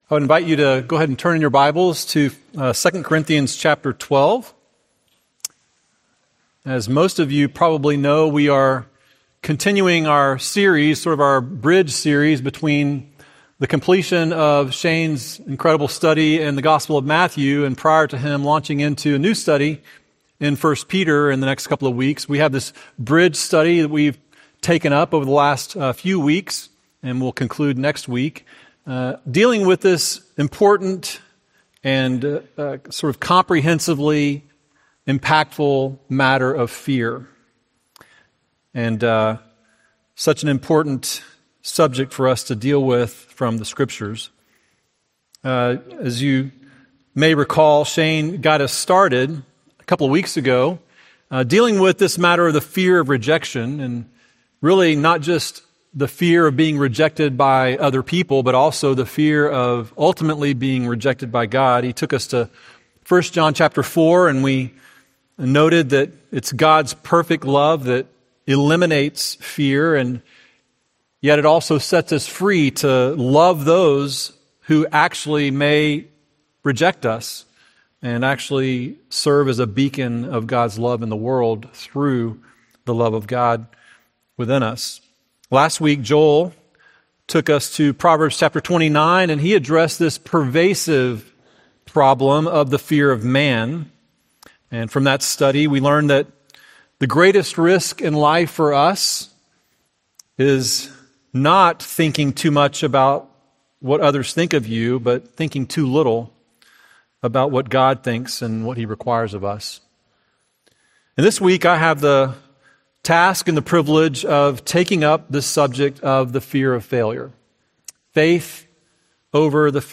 Series: Faith Over Fear, Sunday Sermons